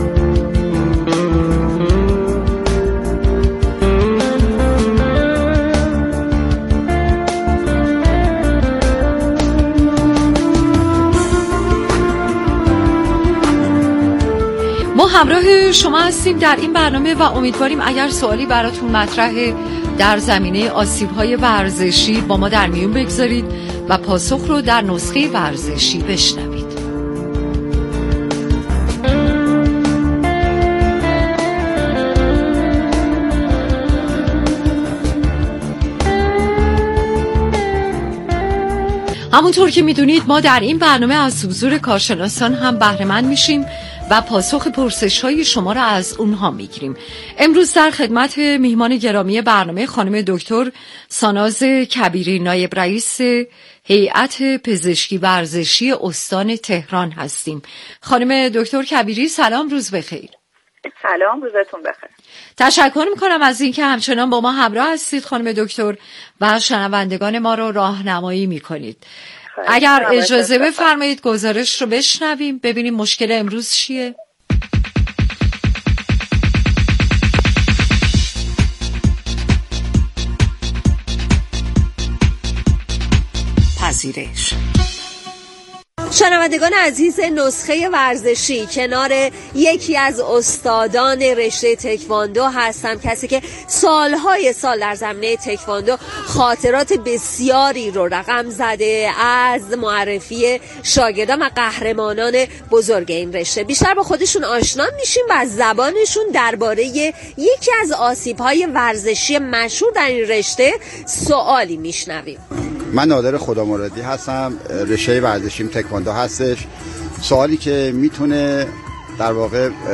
در گفت و گو با رادیو ورزش تشریح کرد؛